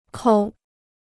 抠 (kōu): to dig out; to pick out (with one's fingers).